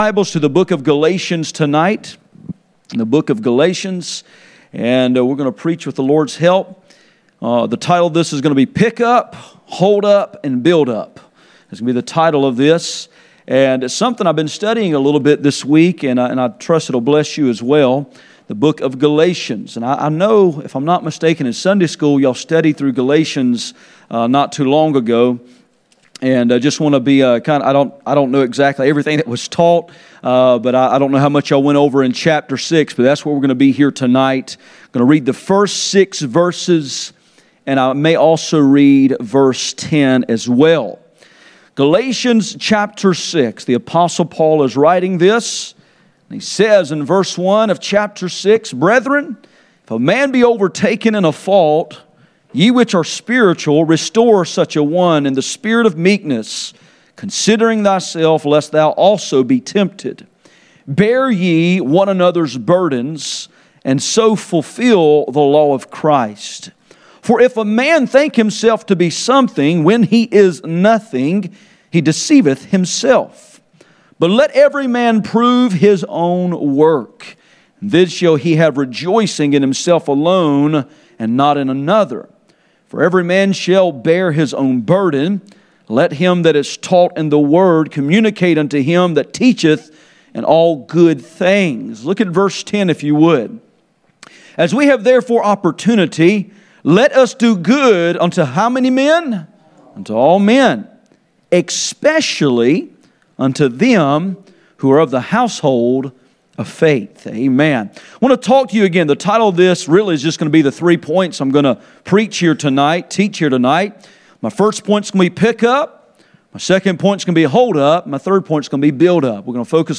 Galatians 5:1-10 Service Type: Sunday Evening %todo_render% « Pray for our Nation Problems stated